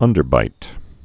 (ŭndər-bīt)